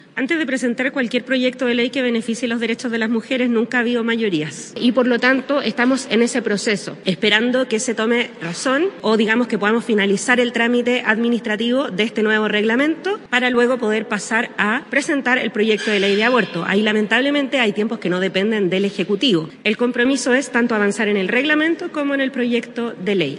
De todas formas, la secretaria de Estado aseguró que el compromiso de presentar el proyecto se va a cumplir y respaldó su viabilidad en el Congreso.
cuna-despenalizacion-aborto-orellana.mp3